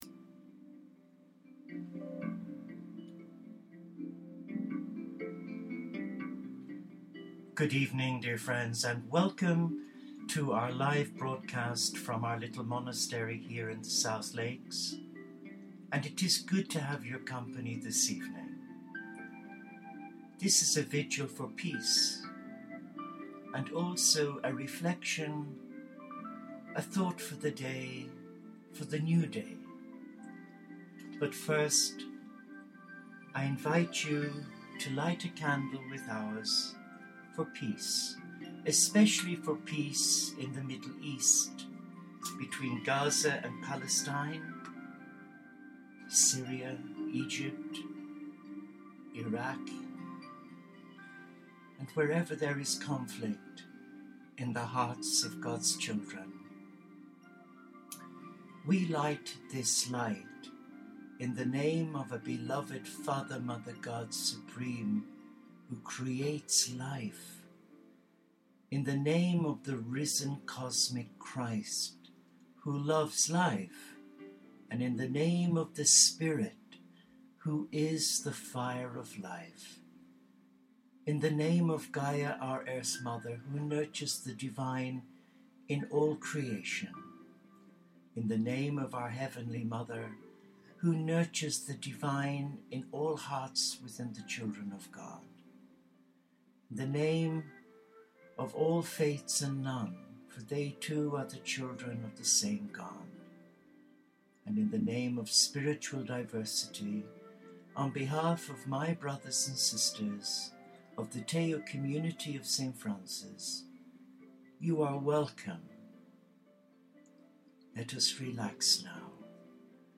Wed July 30 Vigil 'Trust Me in the Depths of U'r Being'